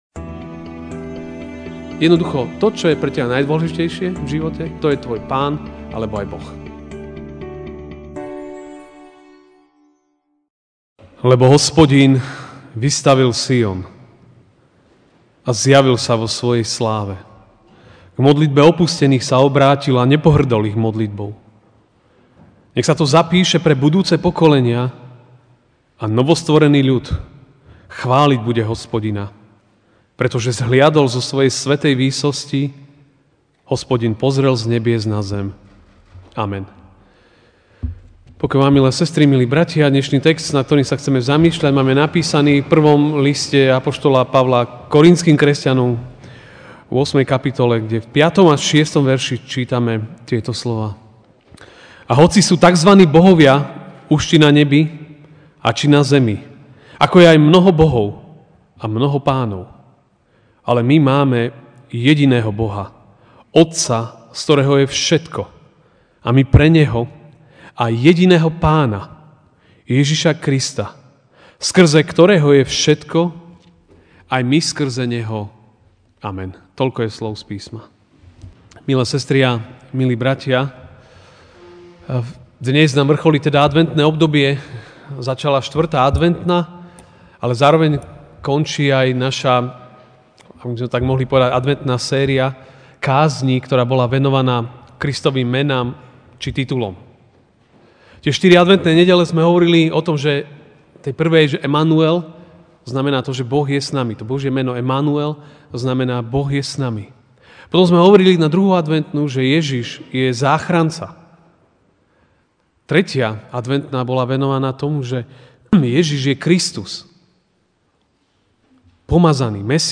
MP3 SUBSCRIBE on iTunes(Podcast) Notes Sermons in this Series Ranná kázeň – Ježiš je Pán!